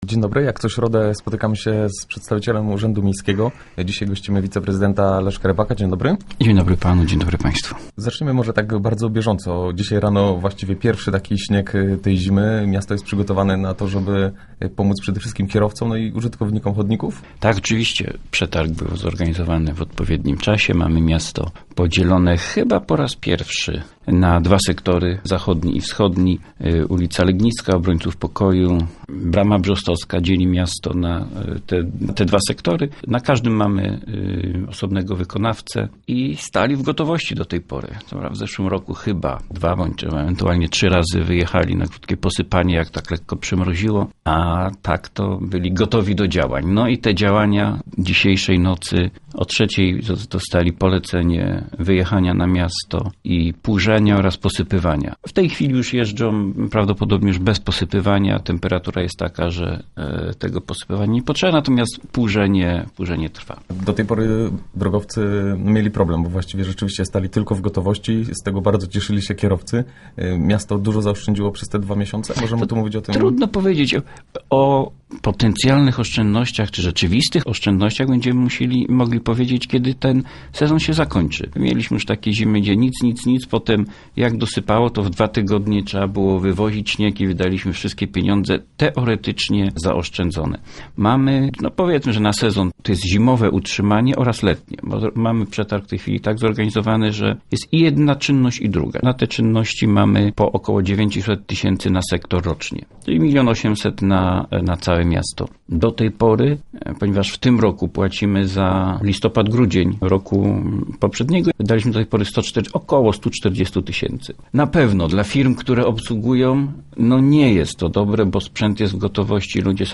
Mi�dzy innymi o tym mówi� w Rozmowach Elki Leszek Rybak, zast�pca prezydenta G�ogowa.